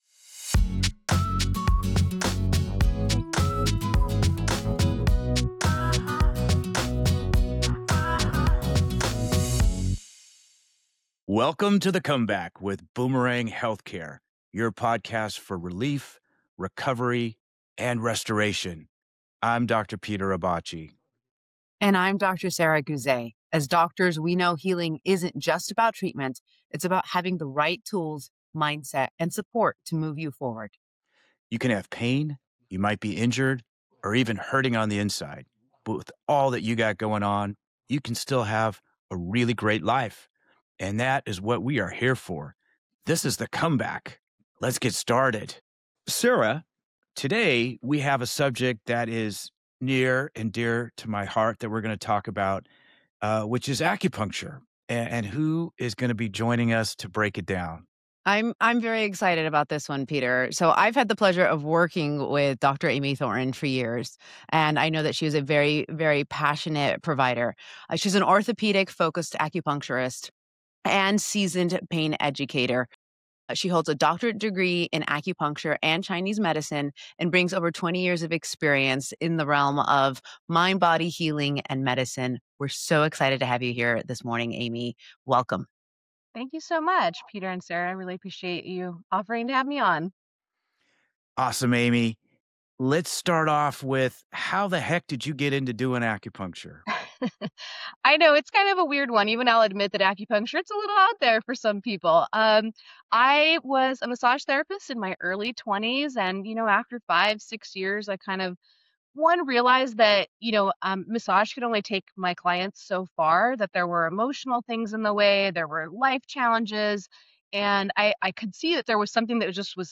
We’re kicking it off with a powerful conversation on acupuncture, chronic pain, and nervous system healing.